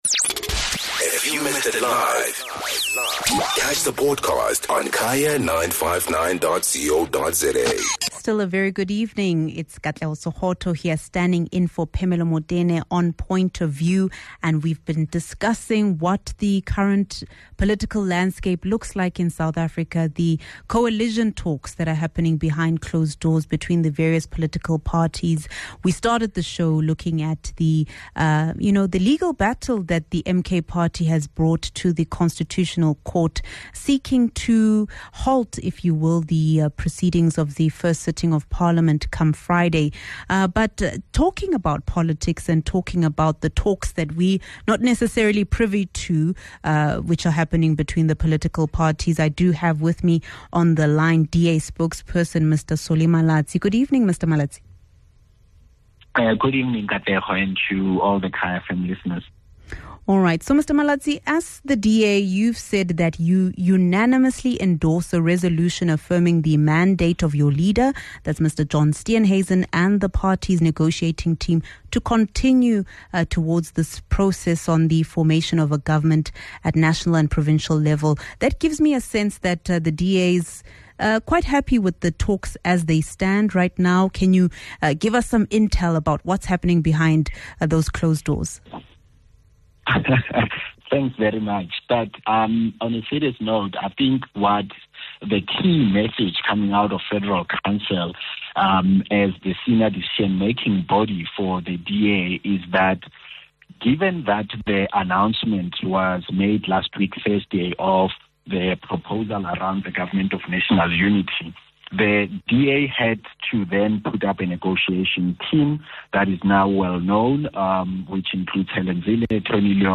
Guest: Malatsi - DA Spokesperson